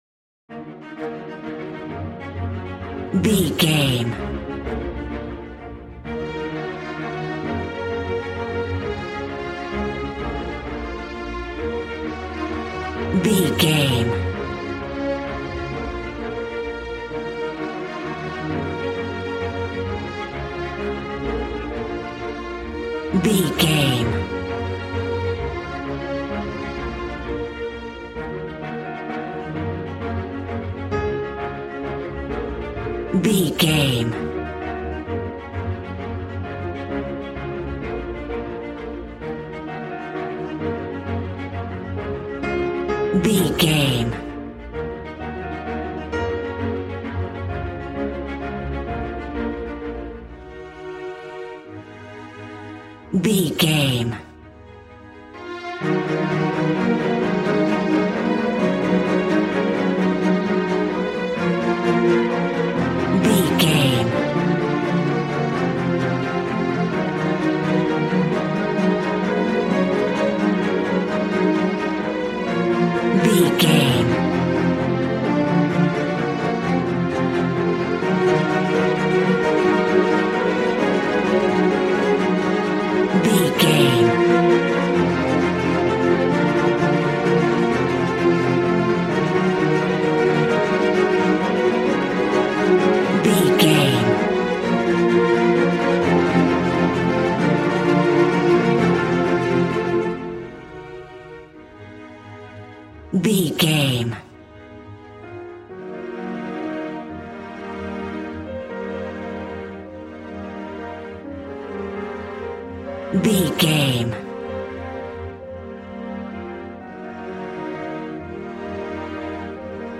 Regal and romantic, a classy piece of classical music.
Aeolian/Minor
B♭
cello
violin
strings